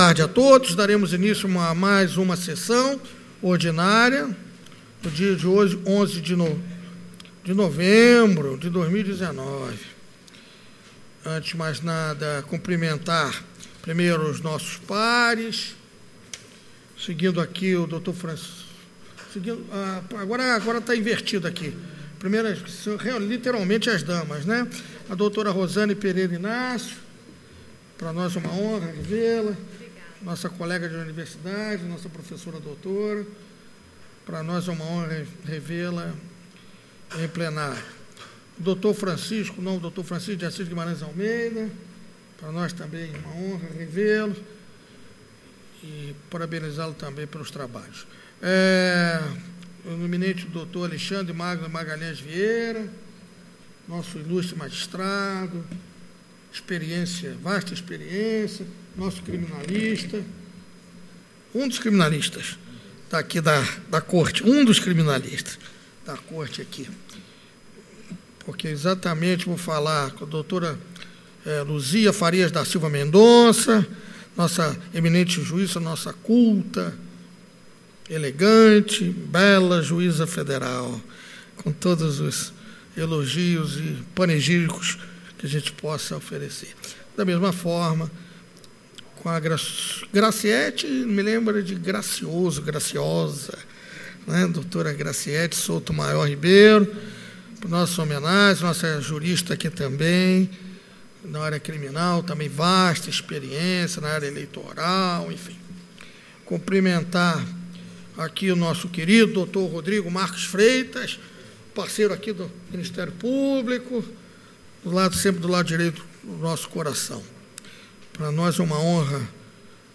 Áudio da 81.ª SESSÃO ORDINÁRIA DE 11 DE NOVEMBRO DE 2019 parte 01